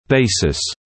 [‘beɪsɪs][‘бэйсис]основа, базис